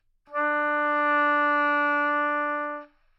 双簧管单音（弹得不好） " 双簧管D4音高稳定性不好
描述：在巴塞罗那Universitat Pompeu Fabra音乐技术集团的goodsounds.org项目的背景下录制。
Tag: 好声音 D4 单注 多重采样 纽曼-U87 双簧管